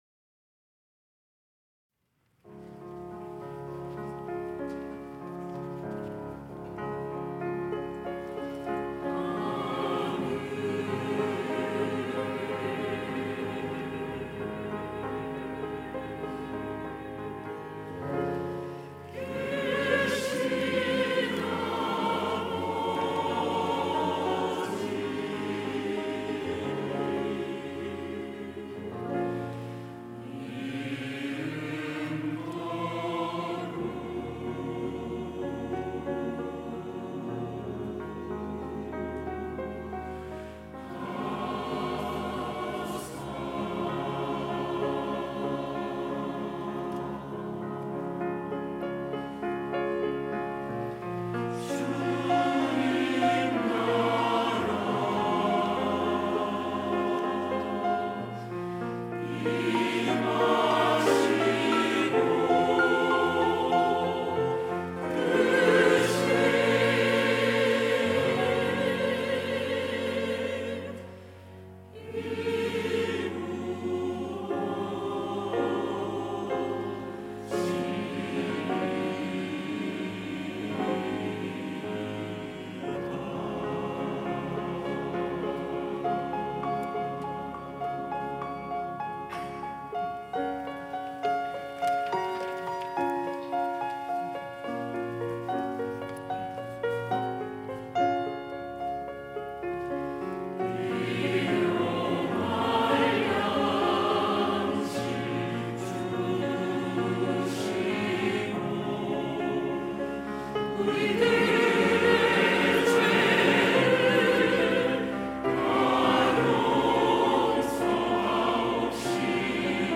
시온(주일1부) - 주의 기도
찬양대 시온